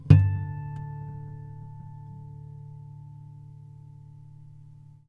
harmonic-09.wav